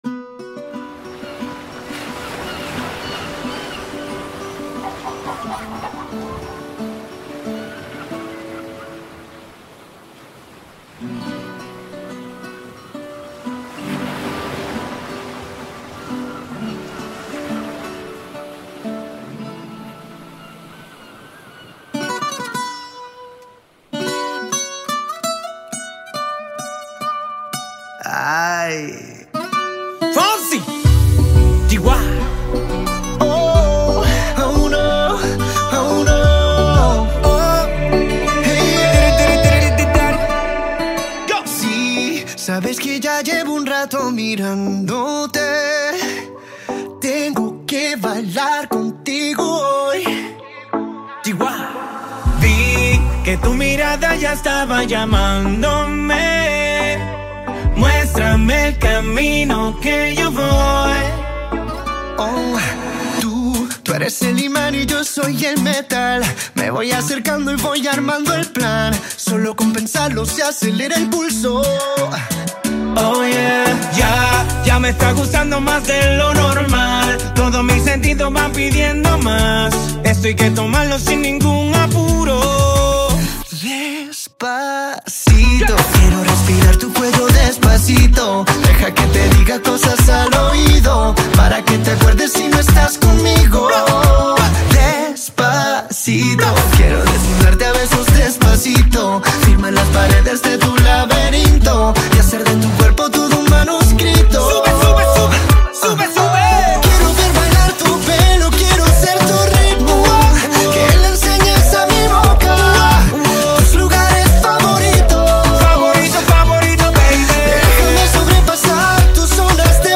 | RnB